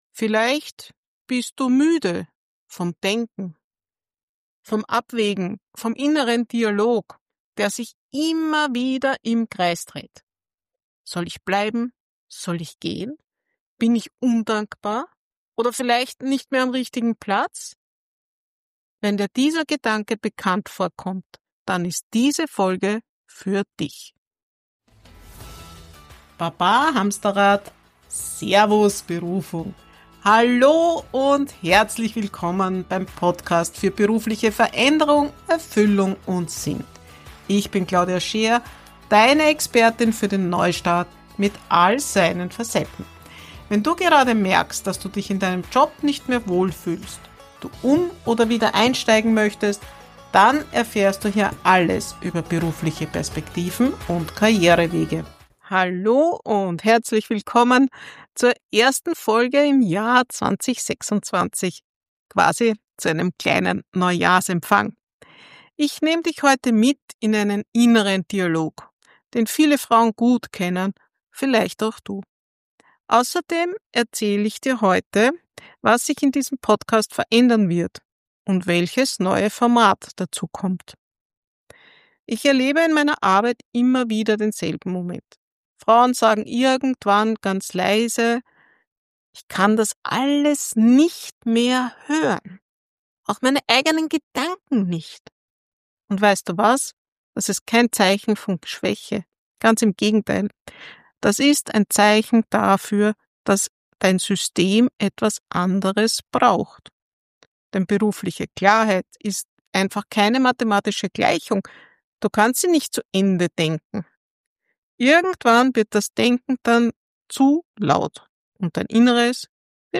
In dieser Solofolge lade ich dich zu einem persönlichen